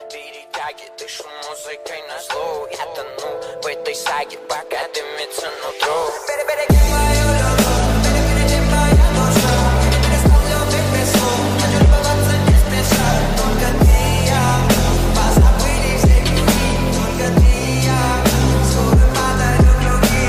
громкие
мощные
Trap